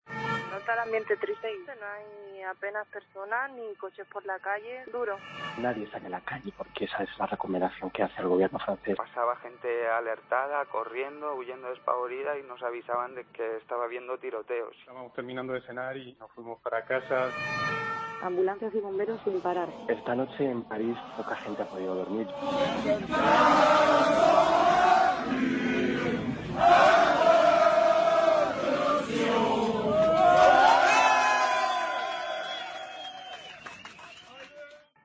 Los testigos relatan a COPE el momento del atentado